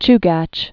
(chgăch, -găsh)